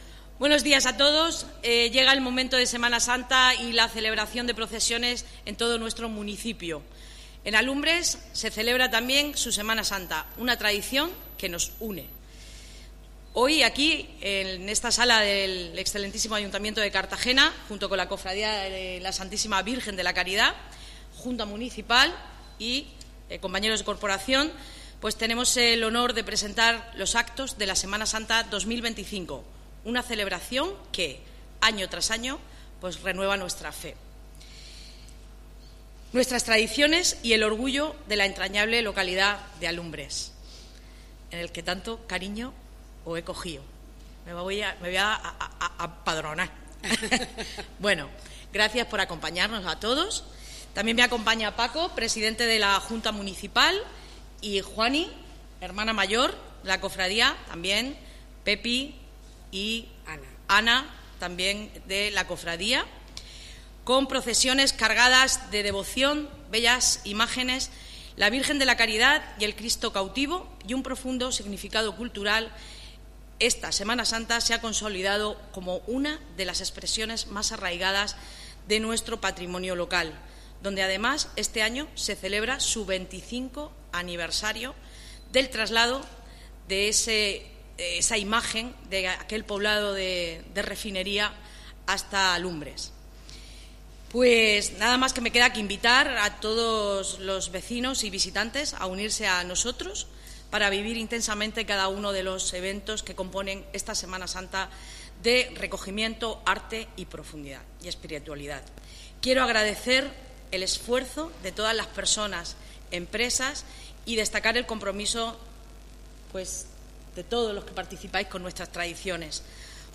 Enlace a Presentación del cartel de la Semana Santa de Alumbres